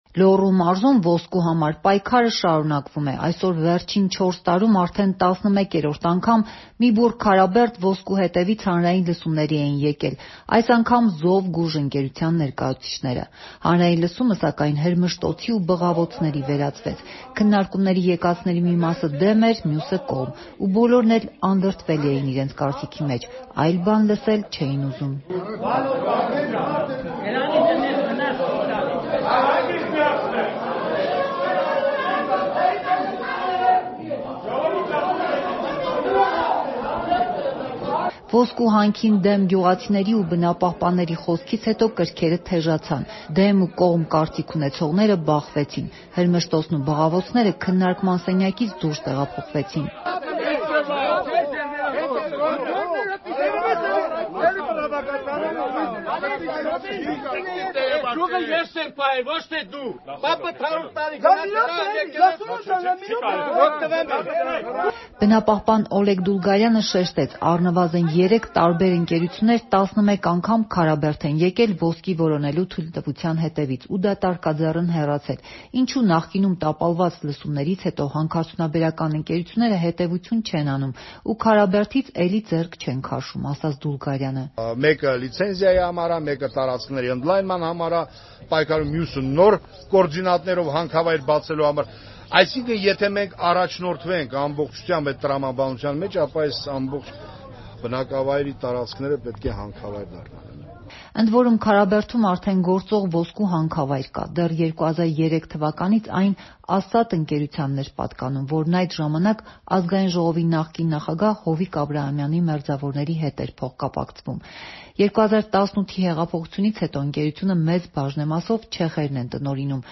Քարաբերդում ոսկու համար պատերազմ. հանրային լսումները վերածվեցին հրմշտոցի ու բղավոցների
Ռեպորտաժներ